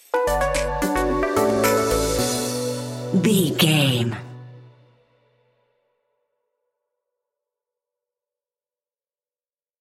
Aeolian/Minor
groovy
dreamy
smooth
futuristic
drum machine
synthesiser
electro house
synth leads
synth bass